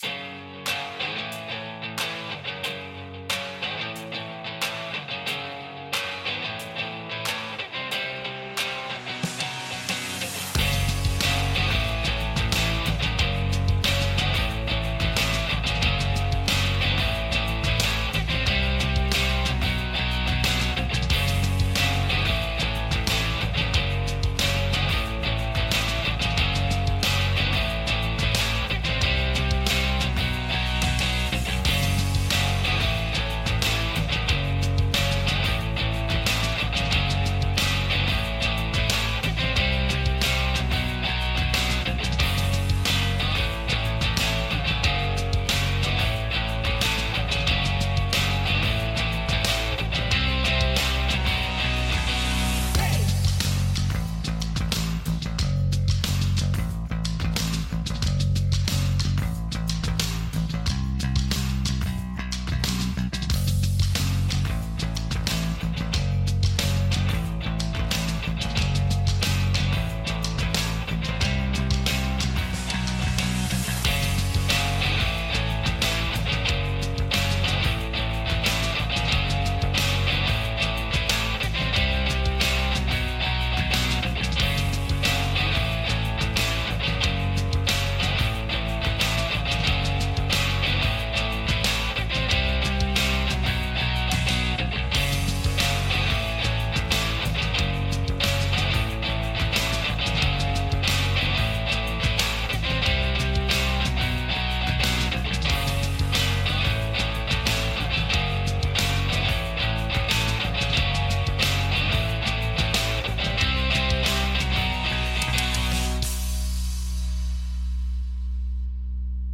[Grunge rock]